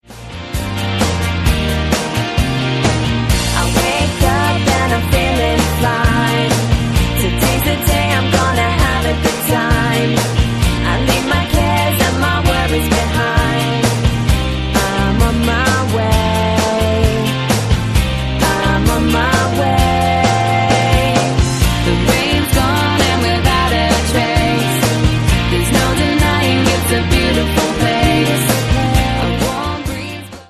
mitreißender und mitsingbarer
• Sachgebiet: Pop